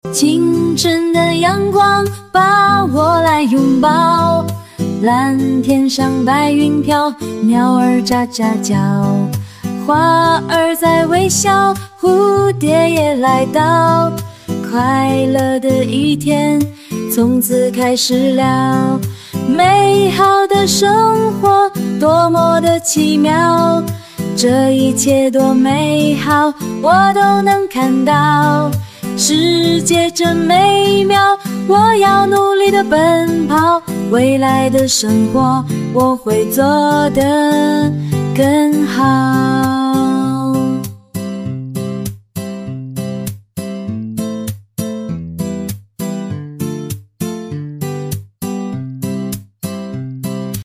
这是我设置 民谣、活力、女声，以“美好生活”为主题生成的歌曲，大家一起听听吧~